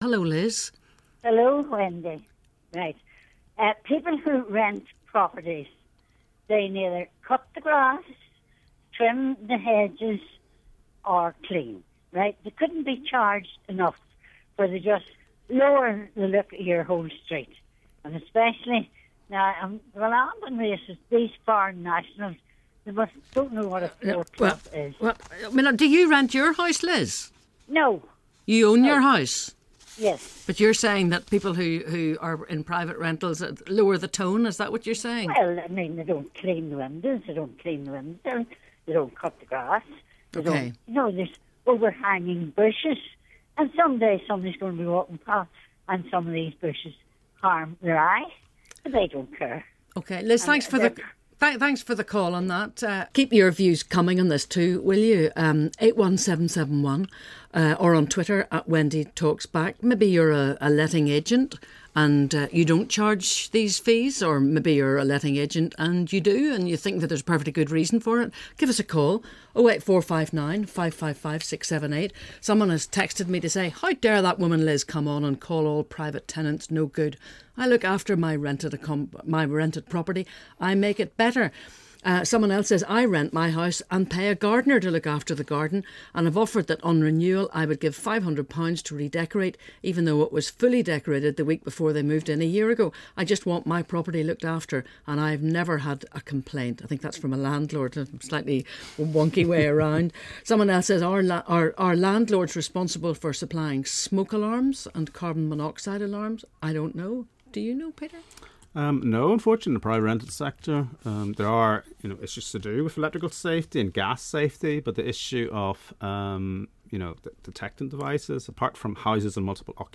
We've been hearing that people are being charged "Lettings Fees." The topic generated reaction from callers and texters.